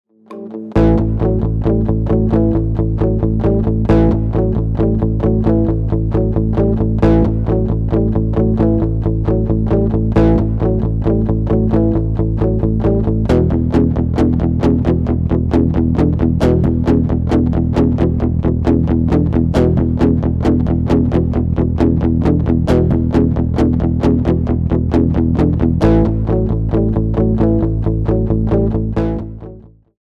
A play-along track in the style of rock.